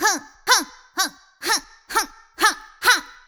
yell-huh-64bpm-0.5beats.ogg